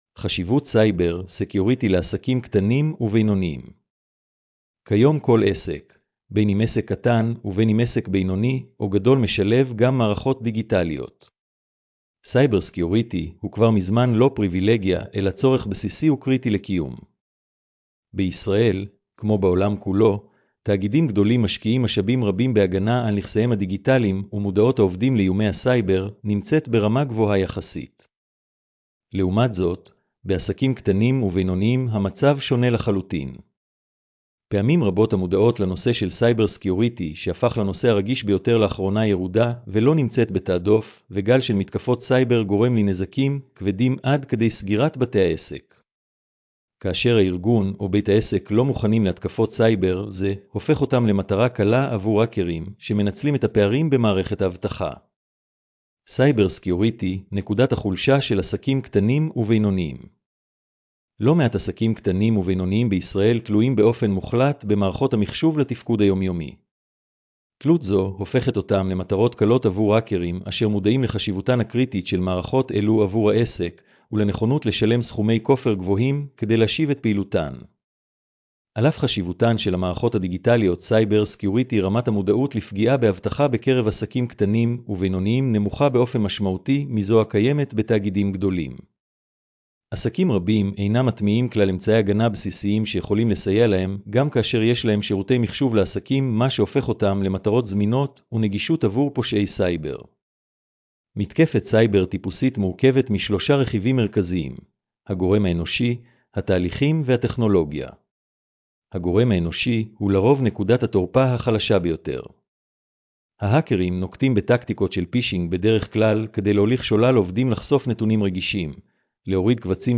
הקראת המאמר לאנשים עם מוגבלות: